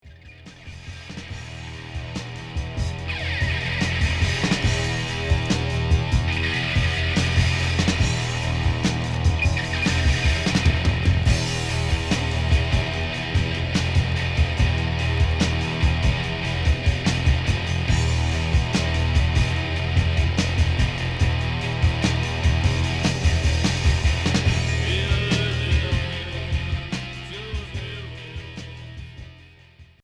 Cold wave